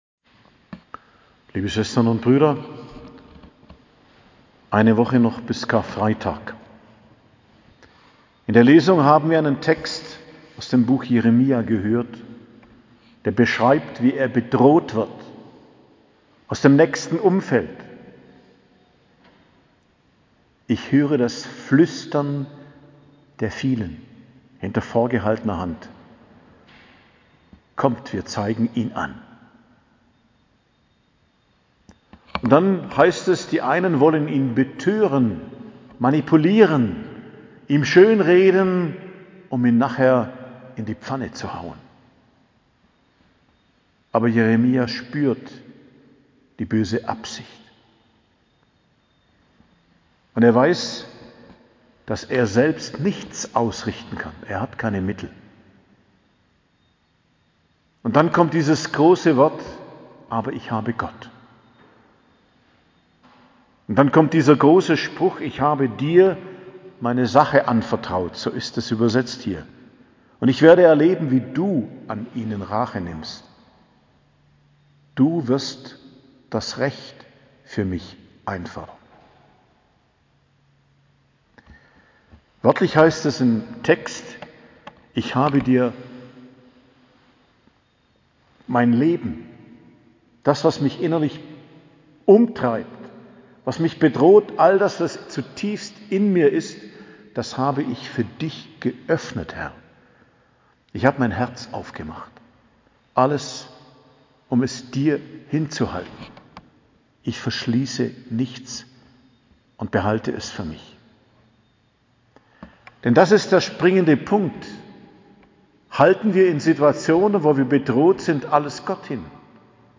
Predigt am Freitag der 5. Woche der Fastenzeit, 8.04.2022